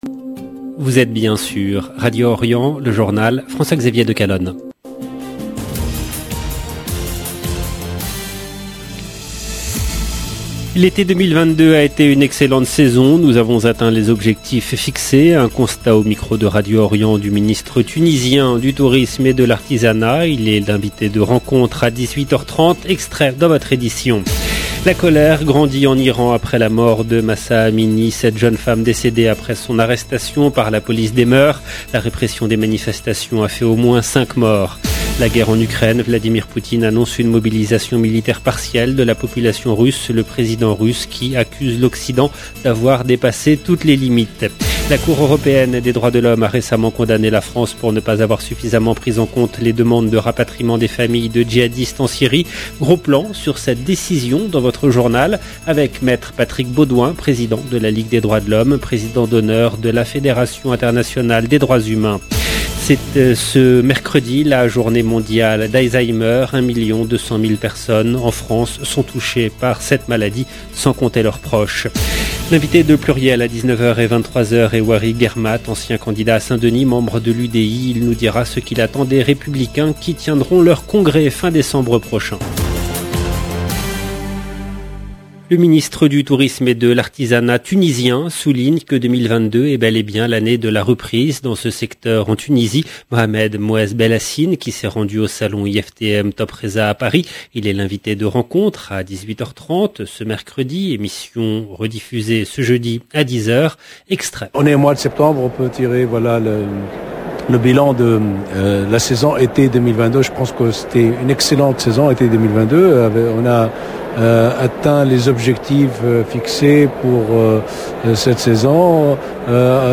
LE JOURNAL DU SOIR EN LANGUE FRANCAISE DU 21/9/2022